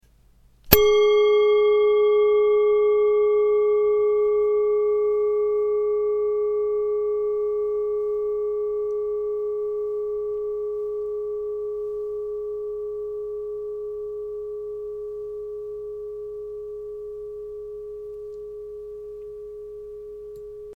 3 Klangschalen für die Klangmassage
Sehr gute Klangqualität - sehr lange anhaltender Klang
Grundton 419,93 hz
1. Oberton 1151,9 hz